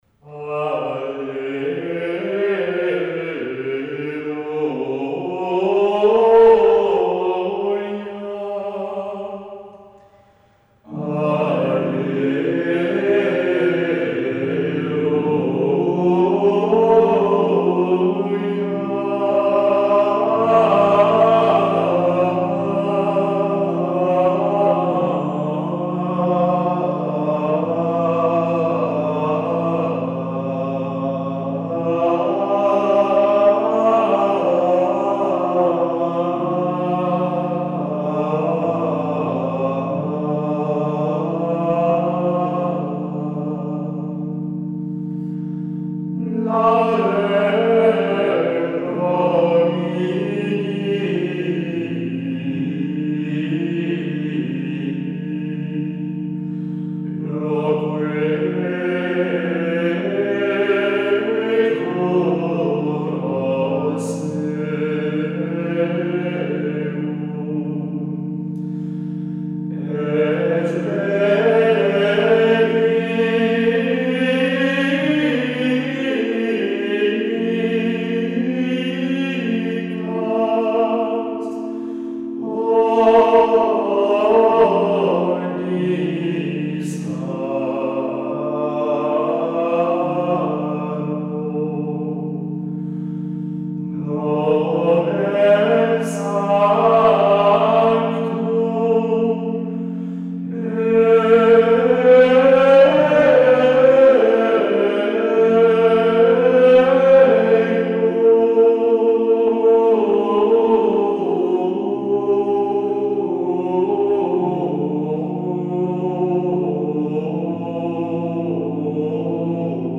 • Pièces grégoriennes de la fête du Saint Nom de Jésus (forme extraordinaire du rit romain)
Les cinq chants du Propre de cette fête étaient interprétés par la Schola Bellarmina qui a enregistré une seconde version, en octobre 2009, avec des chanteurs professionnels.